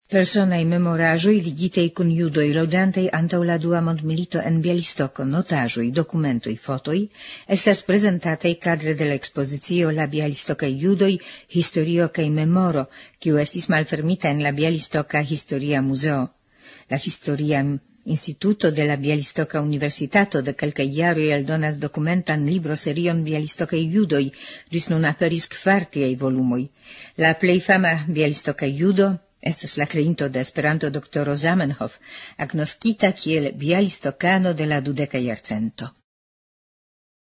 Temo: Sonmaterialo pri mallonga anonco